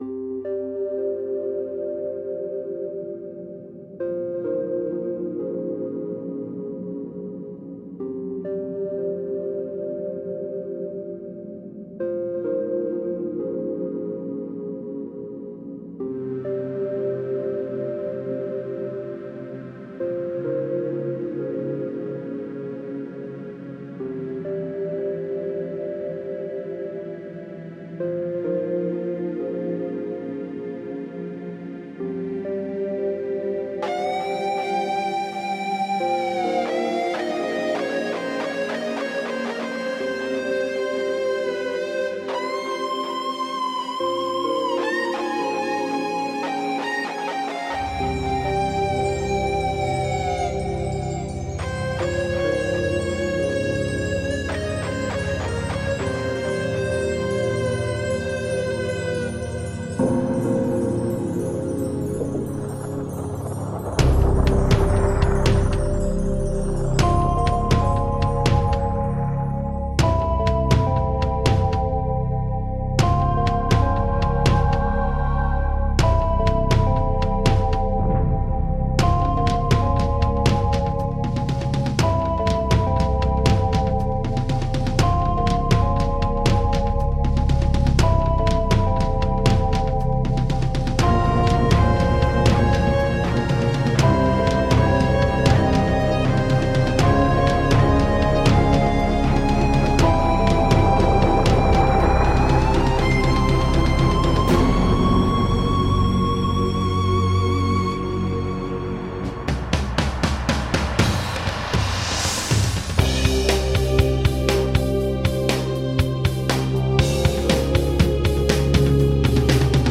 La banda sonora tiene tintes folklóricos en lo que yo he llamado "Un cierto estilo Extremeño"